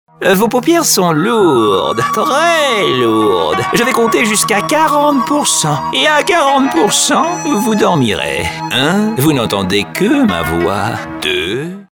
Un timbre de voix allant du médium au grave, Une voix élégante et chaleureuse, avec une large palette de styles d'interprétation et de tons.
Voix off terrifiante, ton horreur et suspense dans ce spot radio réalisé pour la promo de l’attraction ‘Terrorific Night’ à Disneyland Paris.
Voix off terrifiante pour vos publicités Halloween